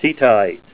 Help on Name Pronunciation: Name Pronunciation: Tiettaite + Pronunciation